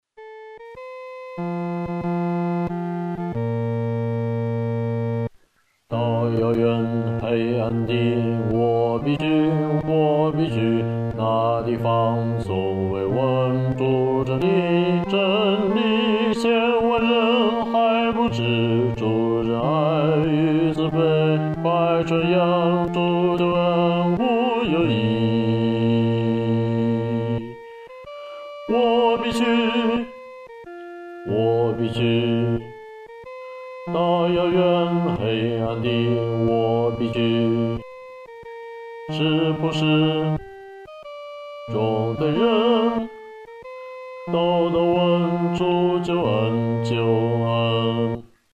男低